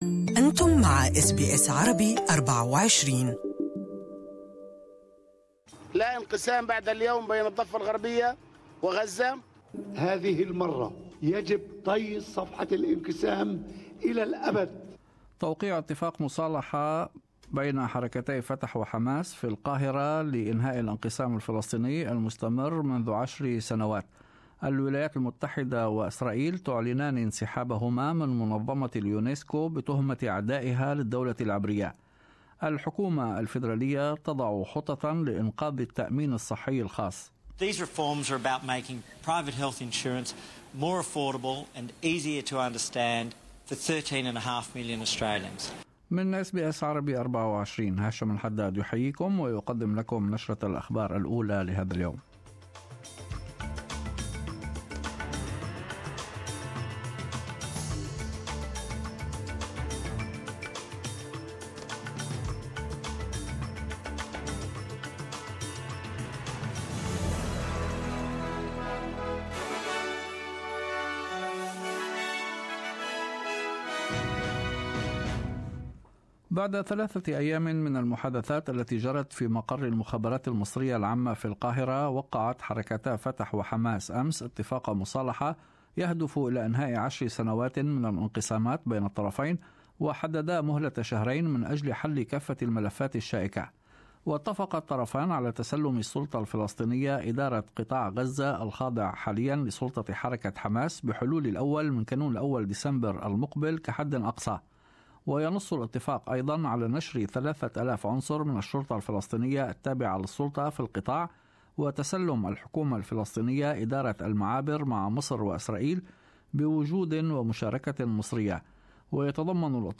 In this bulletin ... ** Palestinian rivals Hamas and Fatah sign a reconciliation deal ** Israel joins the US in withdrawing from UNESCO and ** Australian Boxer Jeff Horn takes out the Don Award